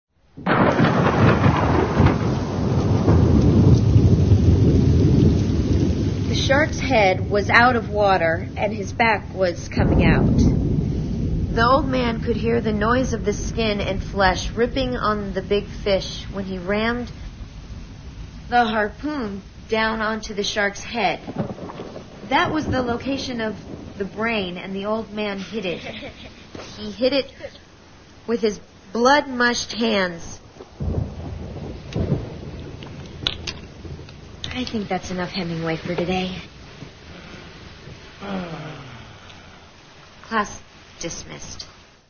The teacher later reads to them from The Old Man and the Sea rather poorly.
Click here to listen to her fumble through the complex verbiage of Ernest Hemingway.